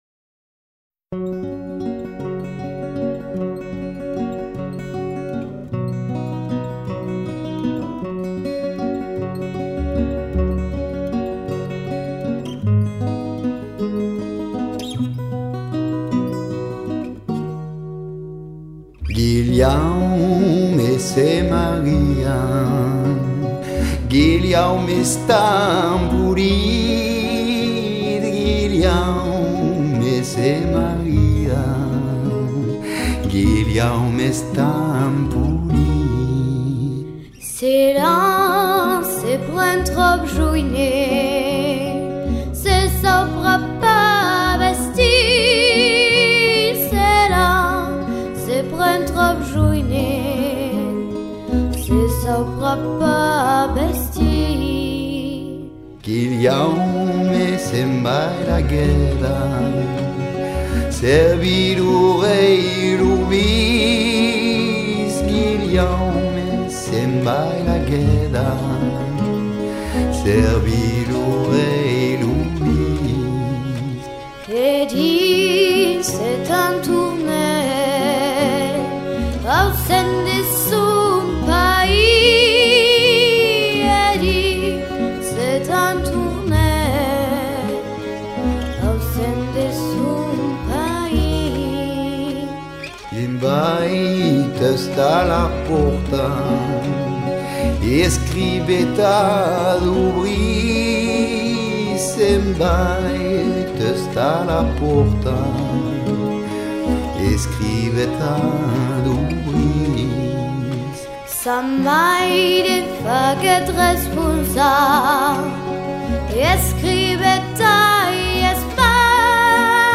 laisse